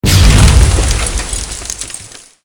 otherdefensivesystemhit.ogg